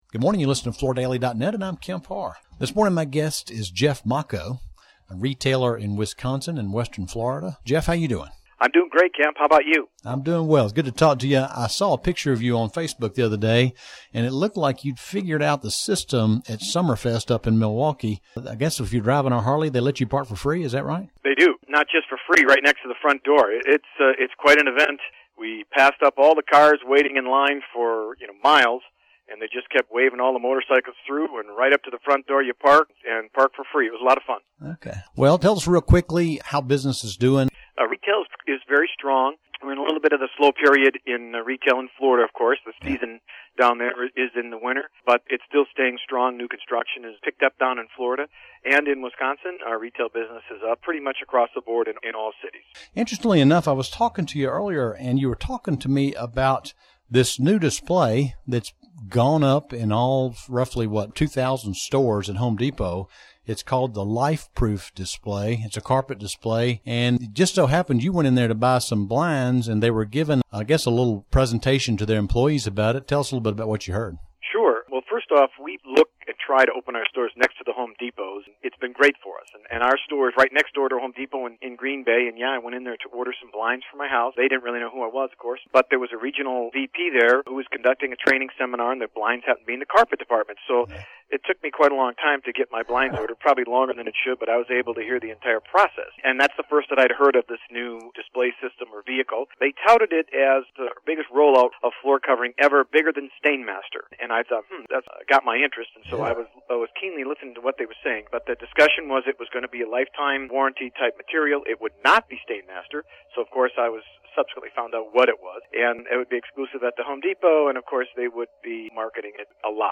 Listen to the interview for details on the product, the magnitude of the launch, the price points, the positioning strategy as well as Home Depot's core focus in the flooring department moving forward.